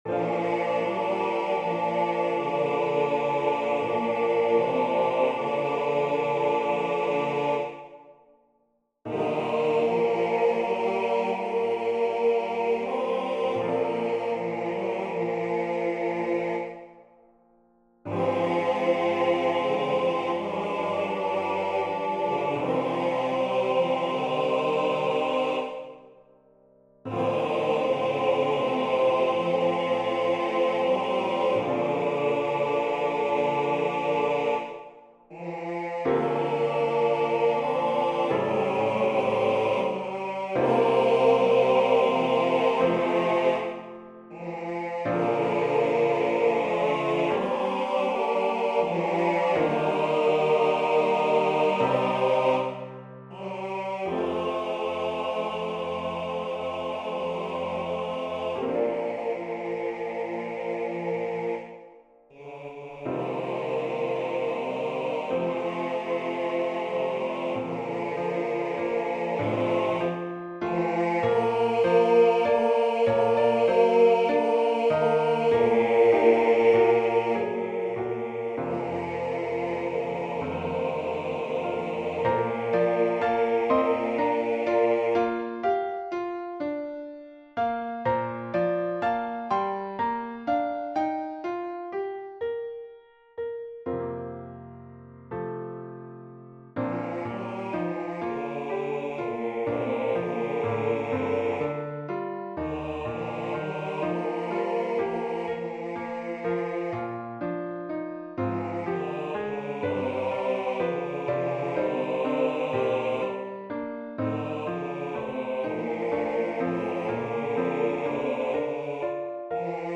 His Eye Is on the Sparrow is a frequently sung and recorded gospel hymn in the African-American community. In this arrangement for men's quartet, I tried to incorporate that gospel sound with the chord progressions and embellishments. The first verse allows for the Lead to take his time, improvise, and follow his heart in singing those beautiful opening lyrics.
Voicing/Instrumentation: TTBB We also have other 17 arrangements of " His Eye Is On The Sparrow ".